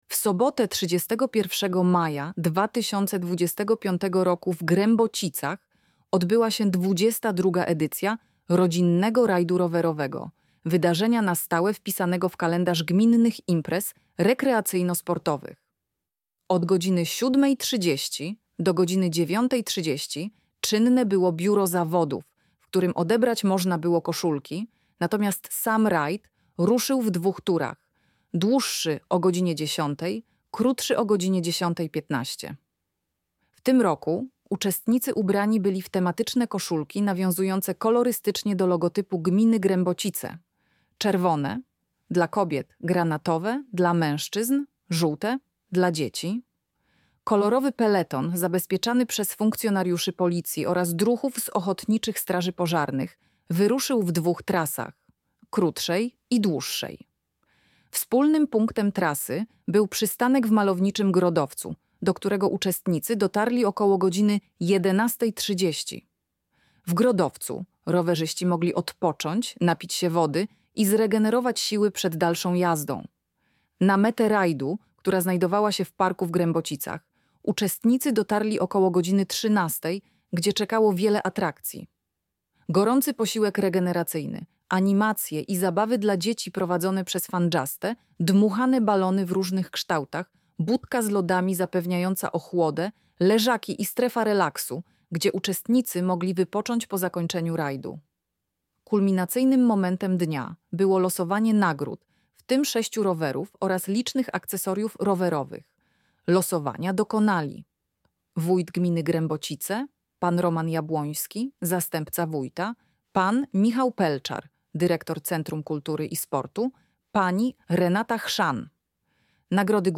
lektor.mp3